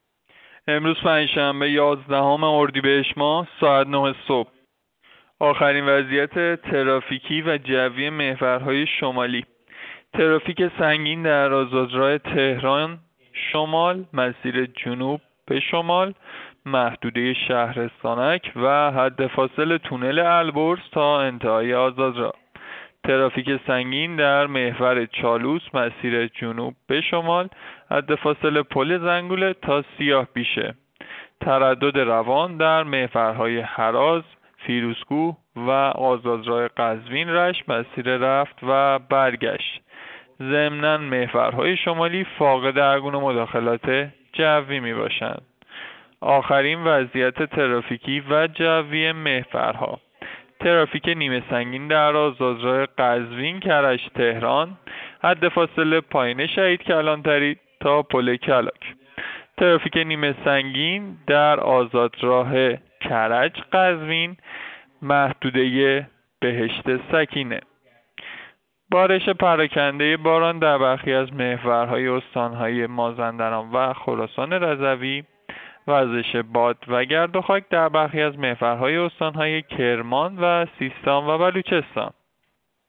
گزارش رادیو اینترنتی از آخرین وضعیت ترافیکی جاده‌ها ساعت ۹ یازدهم اردیبهشت؛